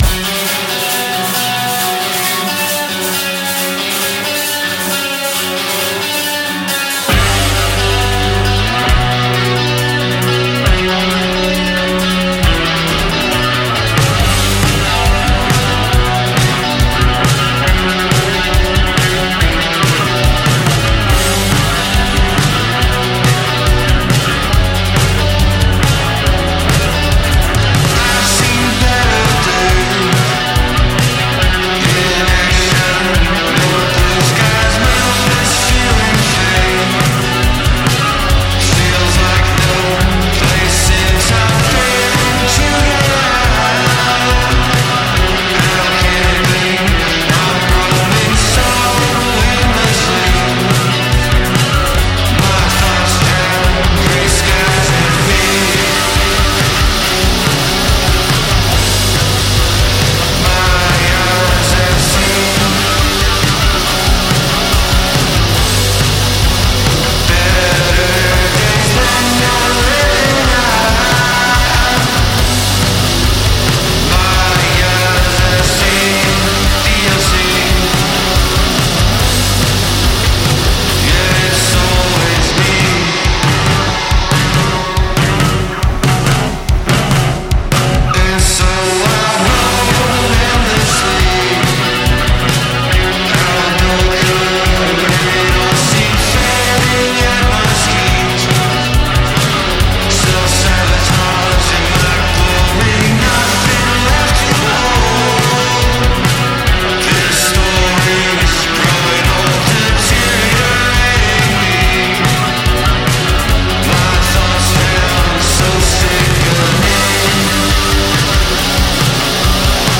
пост панк и готика на итальянский манер